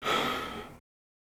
Breath.wav